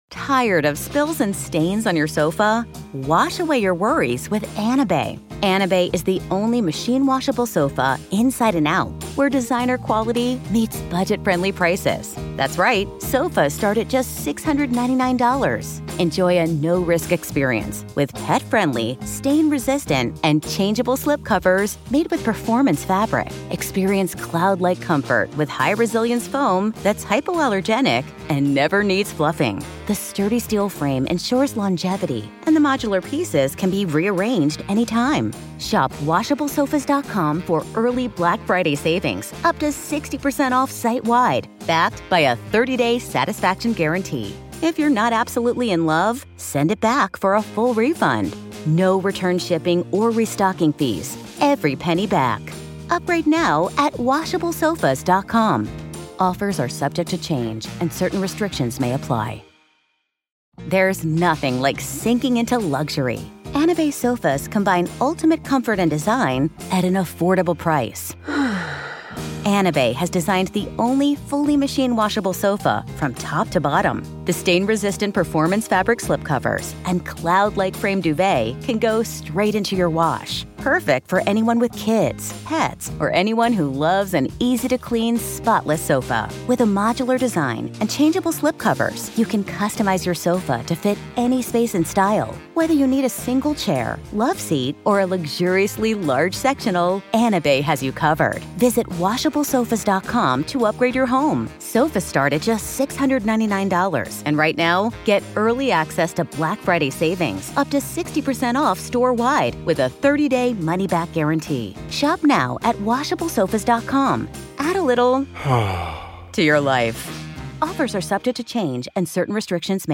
It's the audio-only version of On-Screen Live! from March 27, 2023!